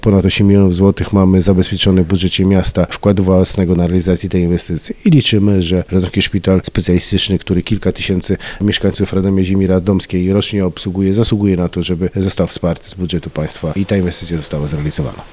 Składamy wniosek do wojewody o współfinansowanie tej inwestycji, mówi wiceprezydent Jerzy Zawodnik: